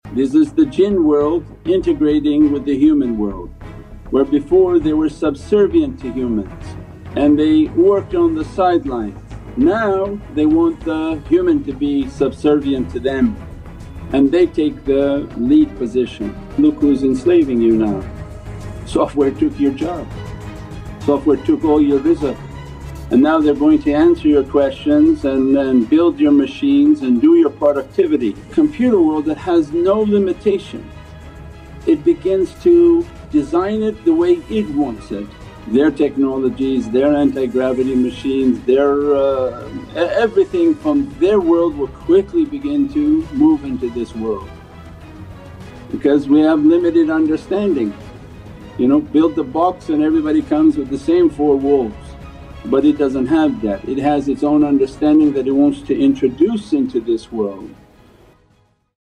Live Talk